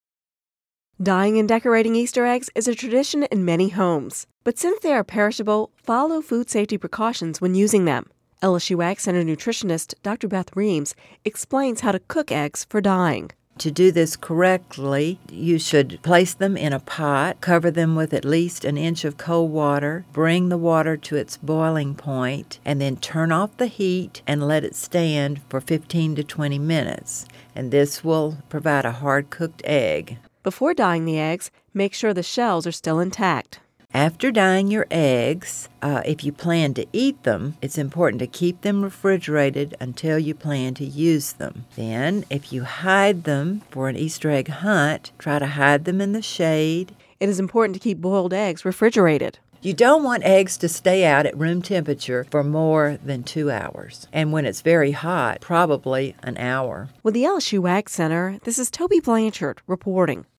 (Radio News 03/29/10) Dying and decorating Easter eggs is a tradition in many homes. But since eggs are perishable, follow food safety precautions when using them.